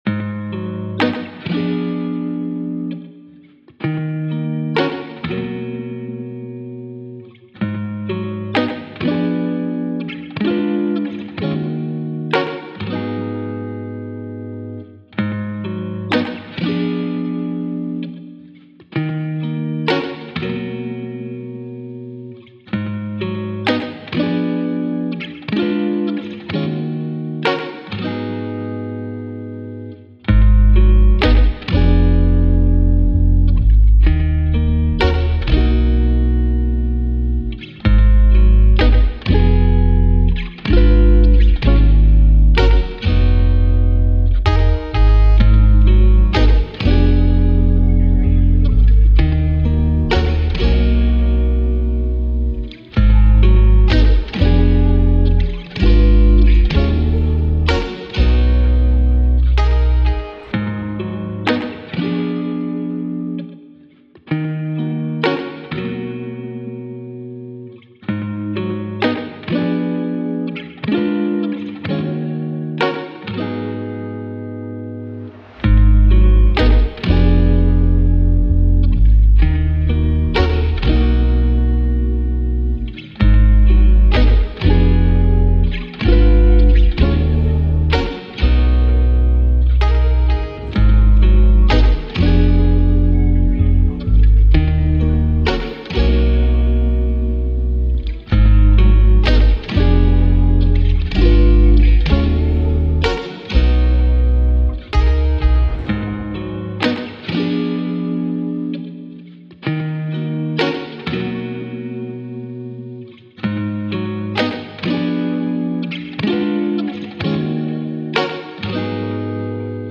R&B, Lofi
Ebmin